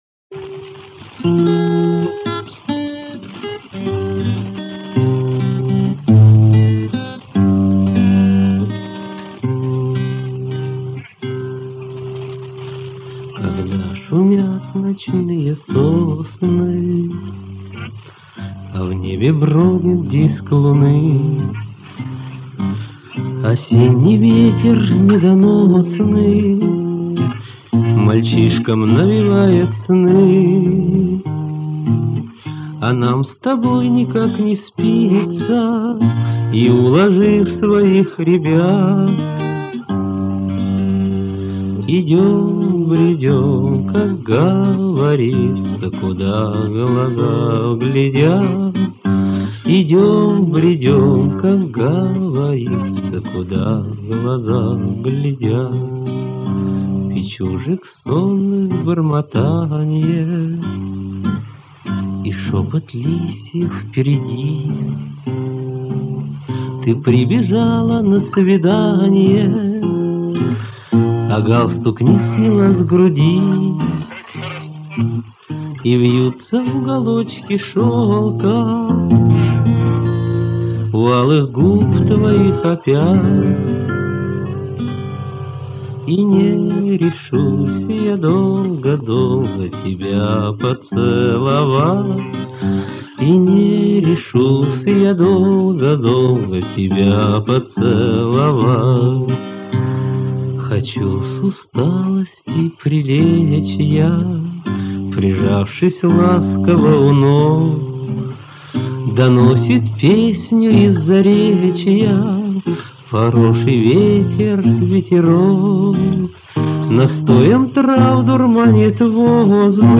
Вожатская лирическая
Неизвестный исполнитель